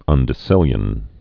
(undĭ-sĭlyən)